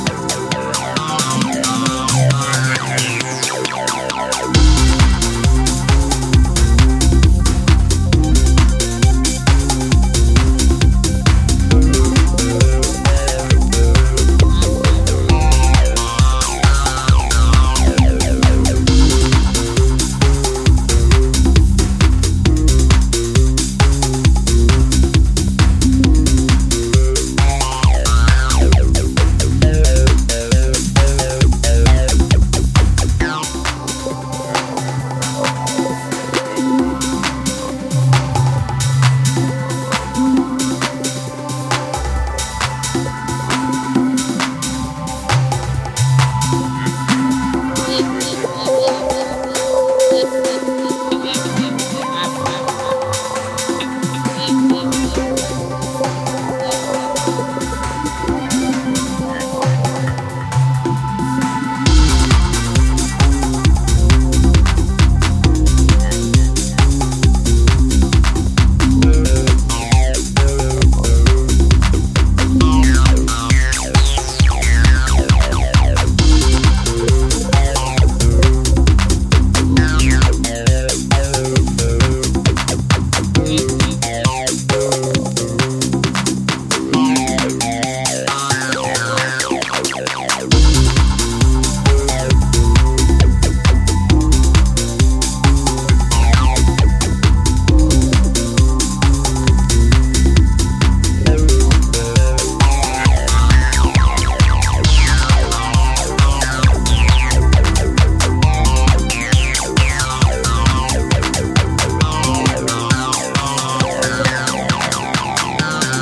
and a fresh perspective on underground dance music